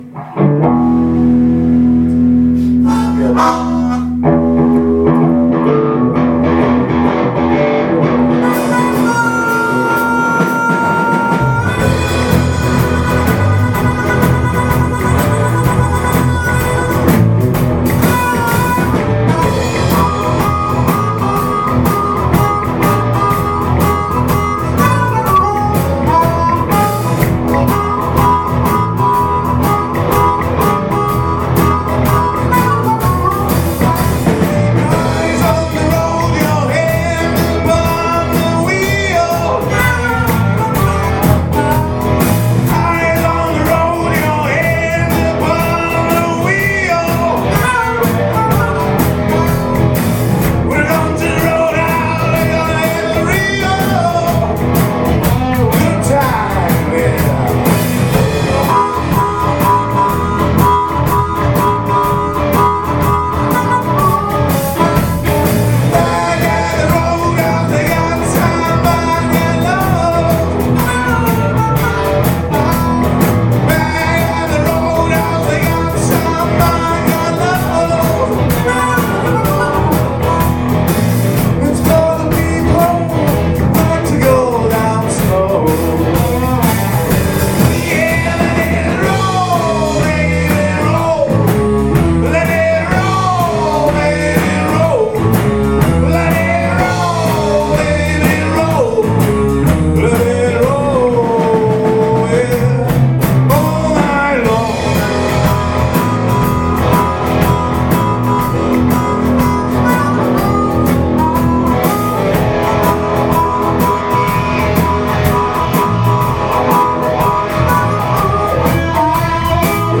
Ukázky ze zkoušky - EKG Blues